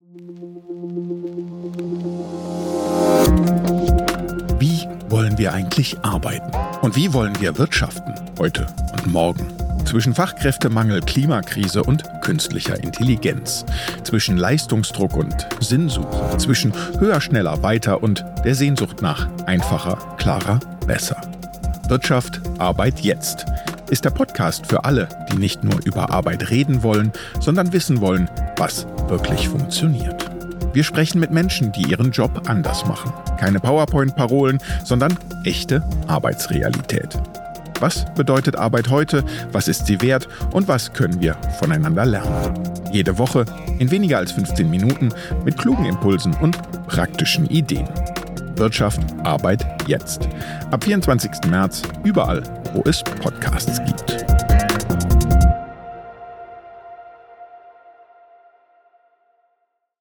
Wirtschaft. Arbeit. Jetzt. | Trailer